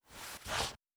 Throw in Long.wav